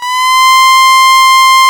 STRS C5 S.wav